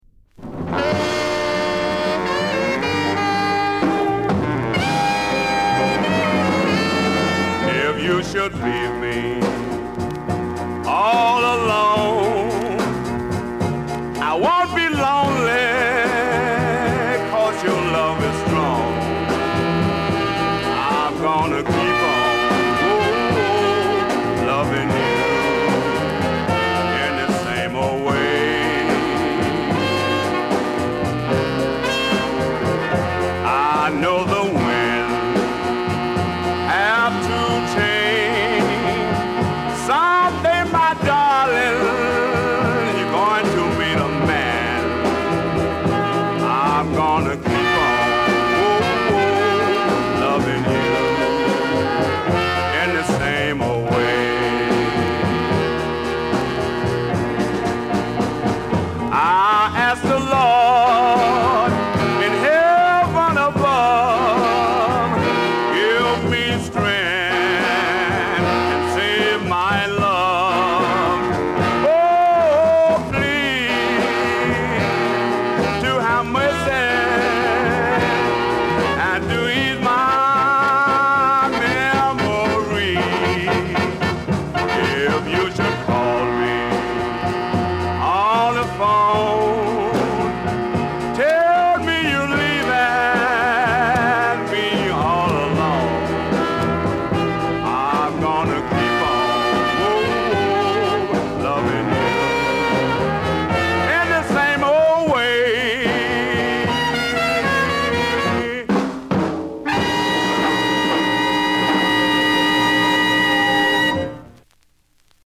勢いたっぷりの演奏にぶっといヴォーカルが絡むロウでファストなガレージR&B。